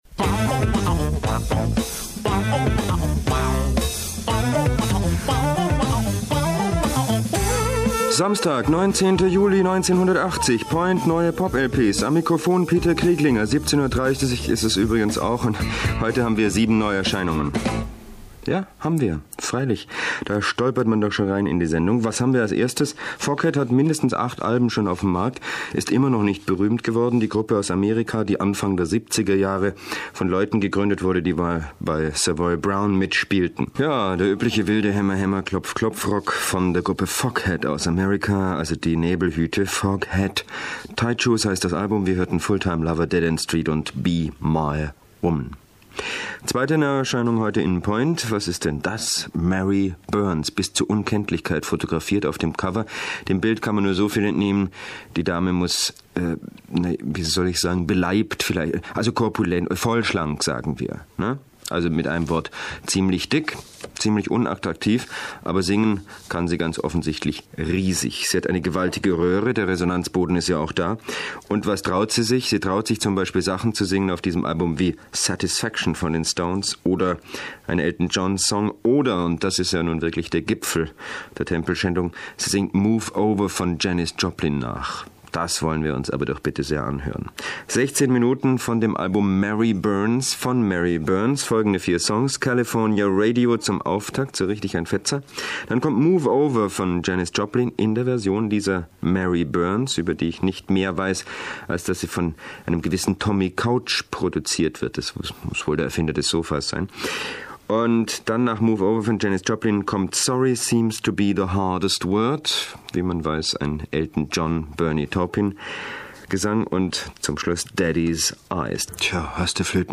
Mitschnitt mit Auszügen aus drei Sendungen, u.a. Point und auch
(wie man es von ihm gewohnt war) teils zynisch, teils etwas boshaft.